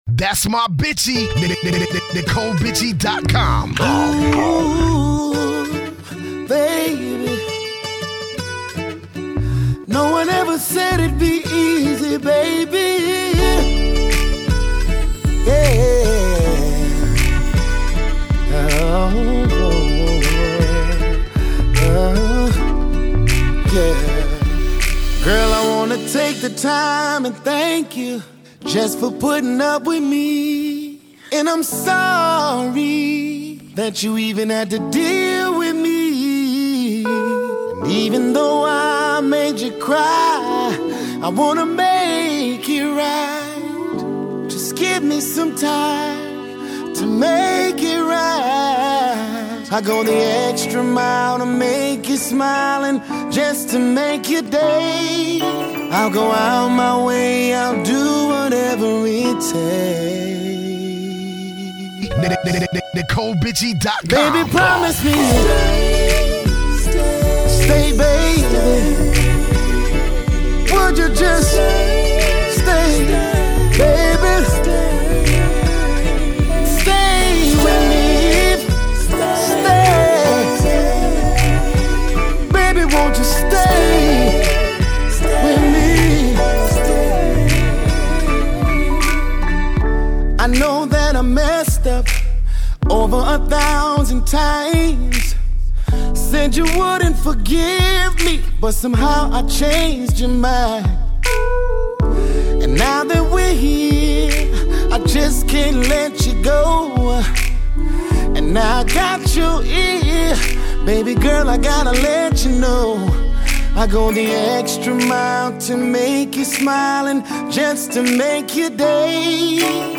R&B Back R&B Back…